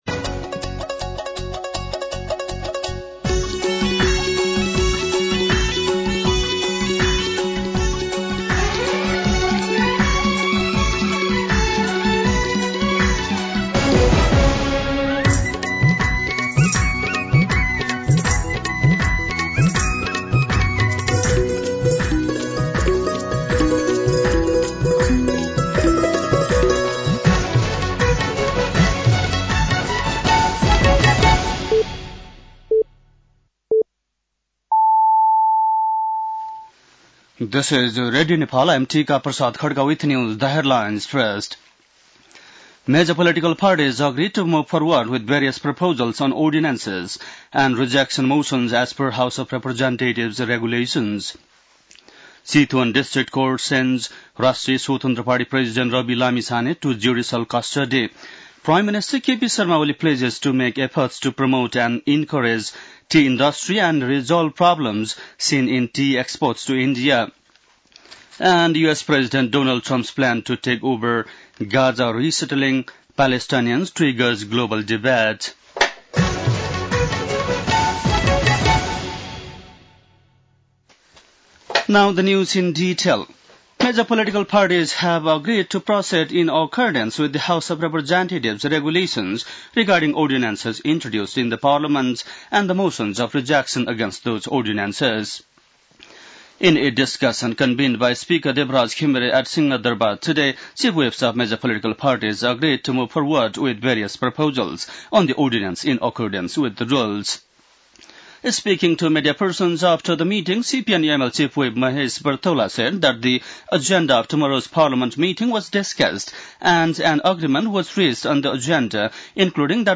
बेलुकी ८ बजेको अङ्ग्रेजी समाचार : २४ माघ , २०८१
8-PM-English-News-10-23.mp3